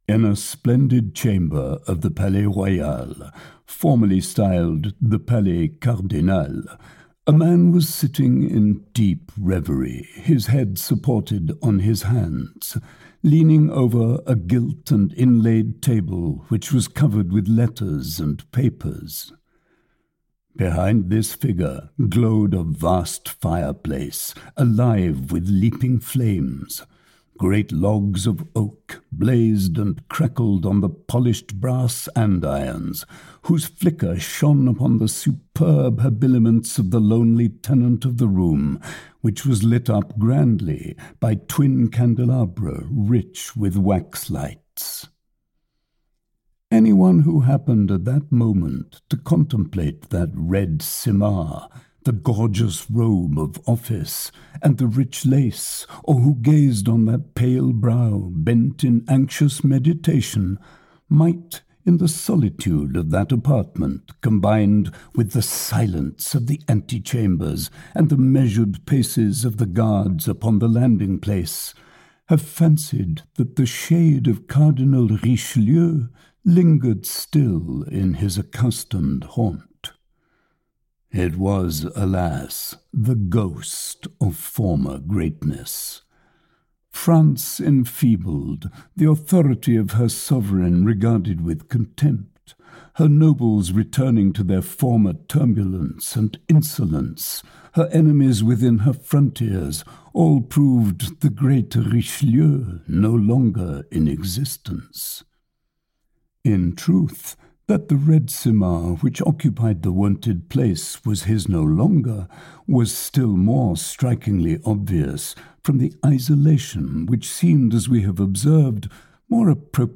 Audio knihaTwenty Years After
Ukázka z knihy